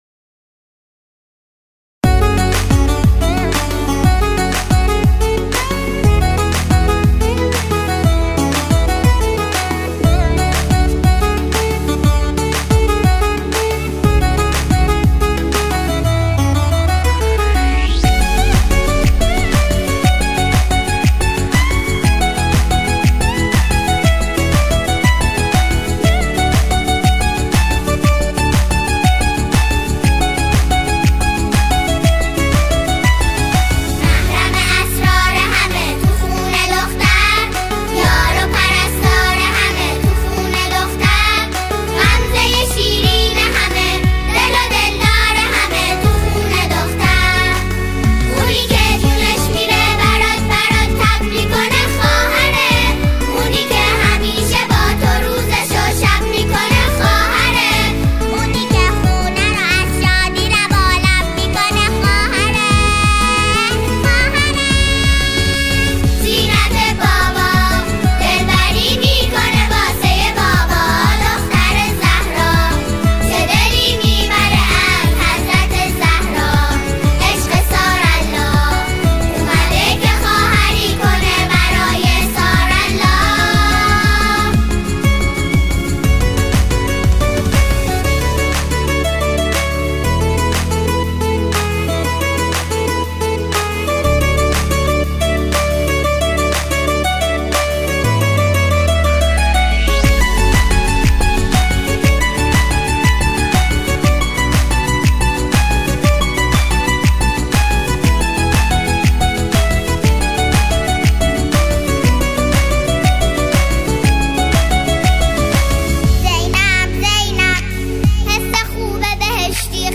اثری شاد و روح‌نواز